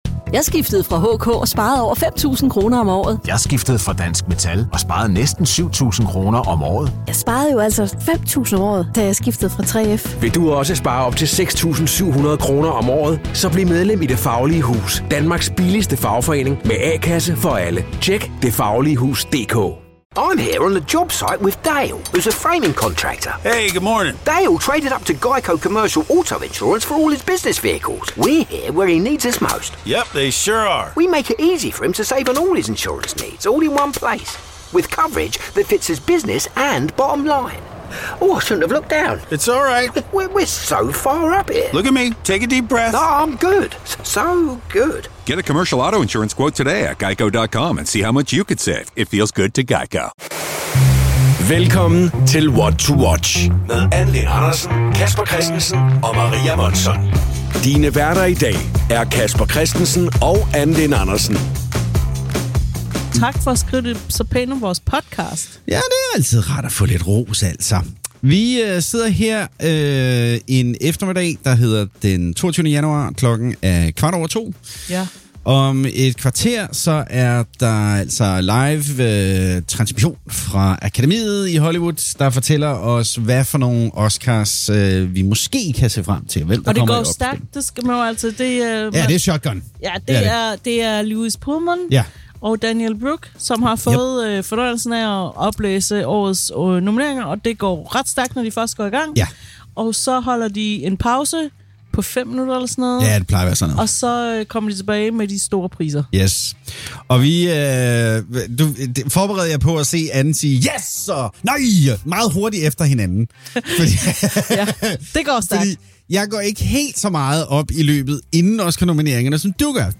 I går tændte vi mikrofonerne og livestreamede, mens årets Oscar-nomineringer blev offentliggjort. Det, du får her, er lyden af vores umiddelbare, ufiltrerede oplevelse. Vi har klippet selve oplæsningen fra Hollywood fra, så du får essensen af vores "live"-oplevelse: Vores snak inden showstart, de hurtige reaktioner i pausen, og vores nedlægning, da støvet har lagt sig. Forvent ikke den dybe, komplette analyse (den kommer senere!), men glæd dig til 100% ærlige følelser, overraskelser og skuffelser.